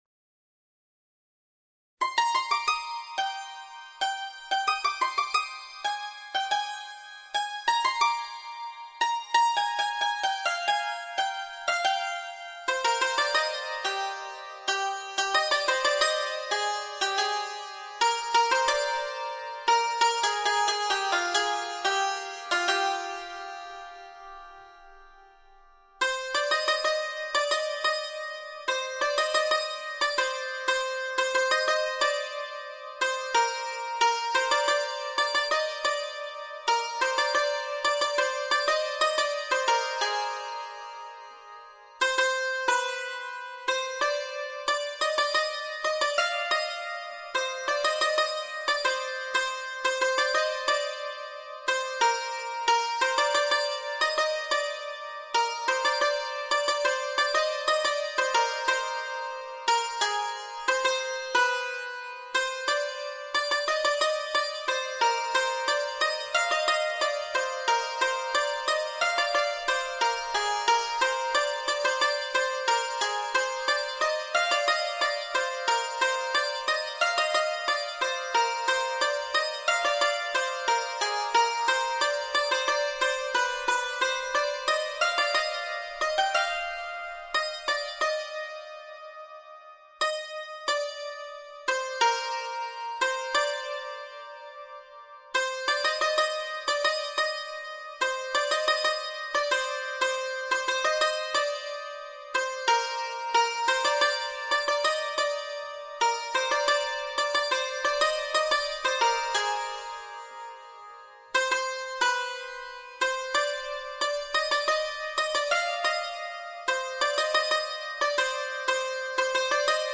تنظیم شده برای سنتور